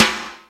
Index of /kb6/Akai_XR-10/Percussion
Rim Shot-02.wav